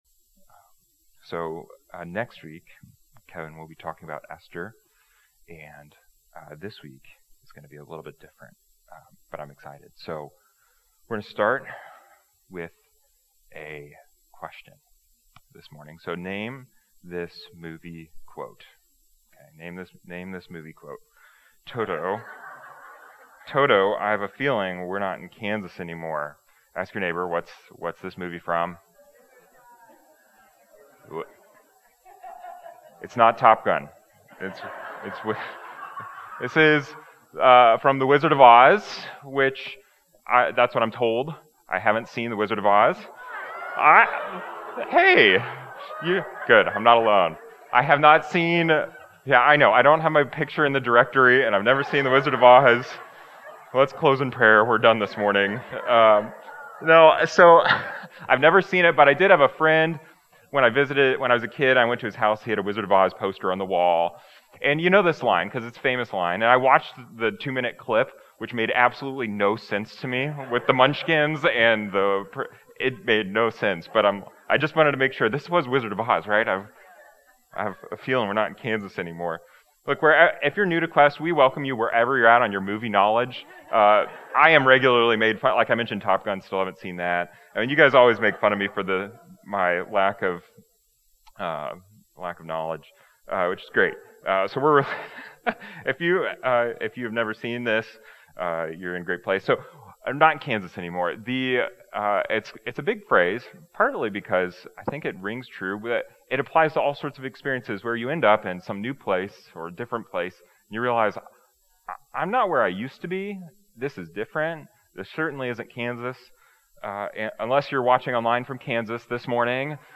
This week continues our series focused on the lives of a few Biblical players that spent their Life in Babylon. This morning's message looks at the lives of Shadrach, Meshach, and Abednego.